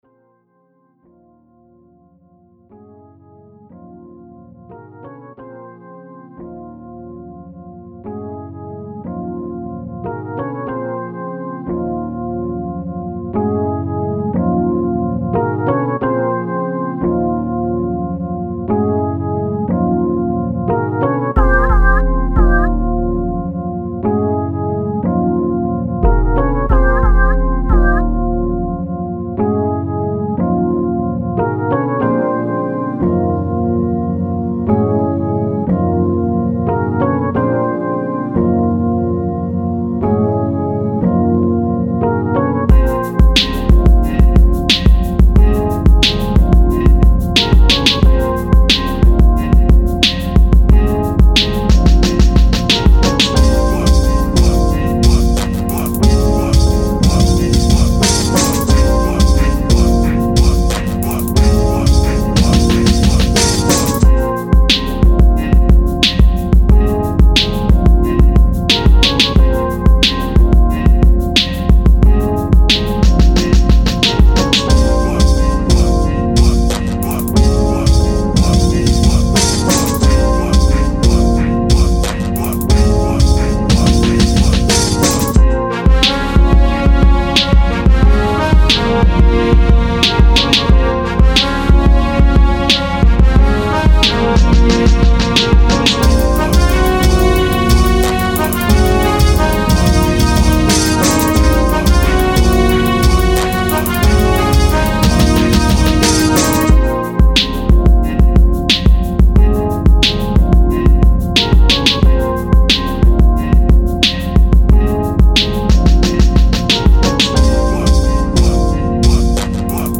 Beat series